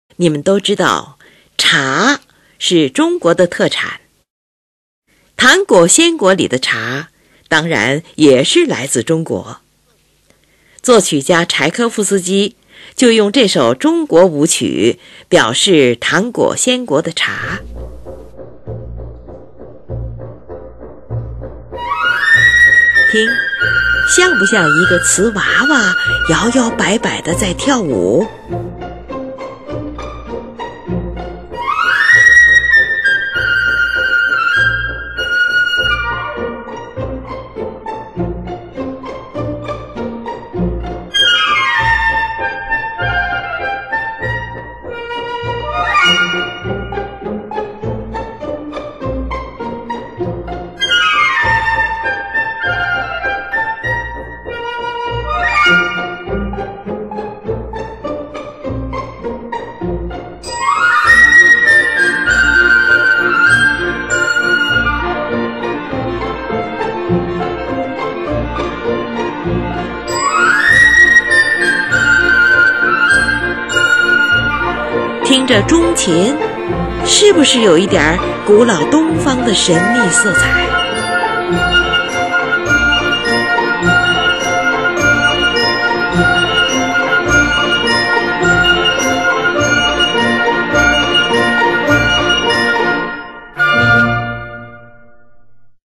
听这钟琴是不是有一点古老东方的神秘色彩。
（6）茶舞，是一首谐谑的中国舞曲。
短笛独奏出尖锐俏皮的旋律与清脆跳跃的弦乐拨奏对答，仿佛有一群滑稽的小瓷娃娃在跳舞。